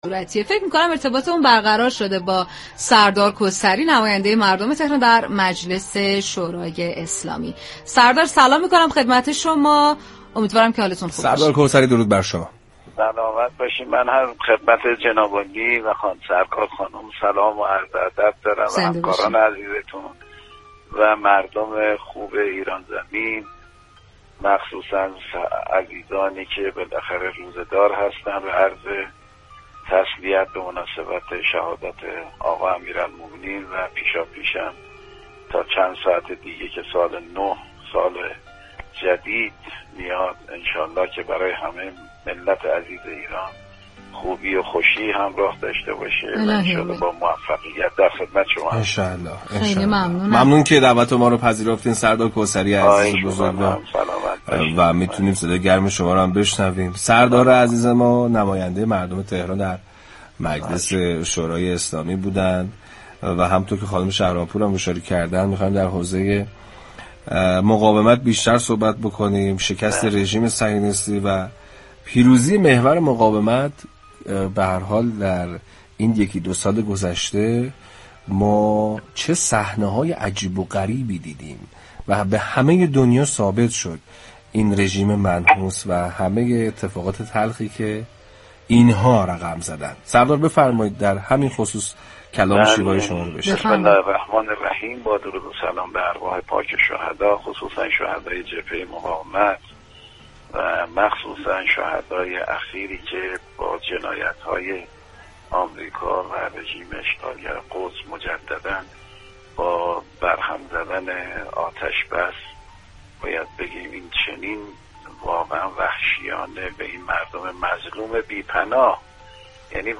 به گزارش پایگاه اطلاع رسانی رادیو تهران، محمداسماعیل كوثری نماینده مردم تهران در مجلس شورای اسلامی در گفت و گو با ویژه برنامه 《قدر بهار》 اظهار داشت: ملت فلسطین برای آزادی سرزمین و اعتقادات خودش مبارزه می‌كند.